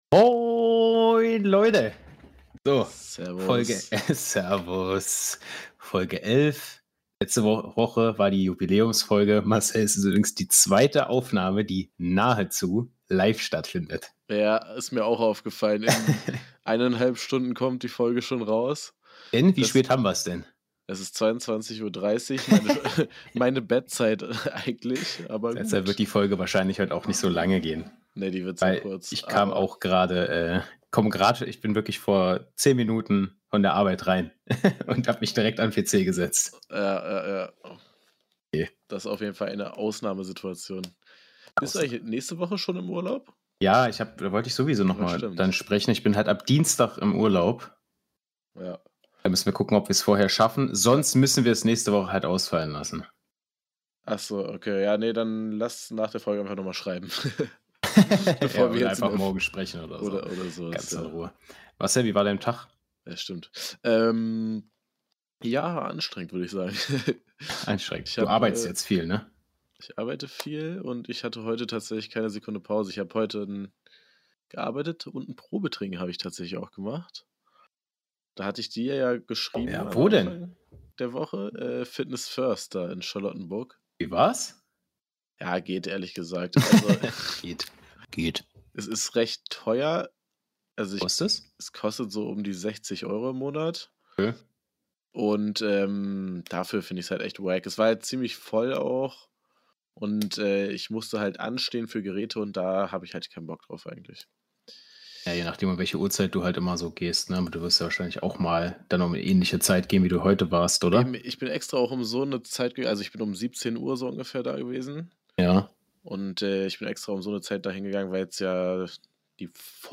Eine kurze knackige Folge frisch in der Nacht zum Donnerstag aufgenommen.